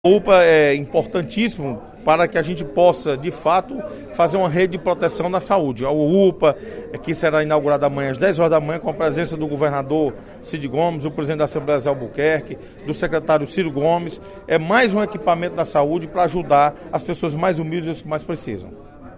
Durante o primeiro expediente da sessão plenária desta quinta-feira (27/02), o deputado Osmar Baquit (PSD) comemorou mais um equipamento de saúde para o município de Quixadá: a Unidade de Pronto Atendimento (UPA 24 horas), construída pelo Governo do Estado, com apoio do Governo Federal, que será inaugurada na sexta-feira (28/02).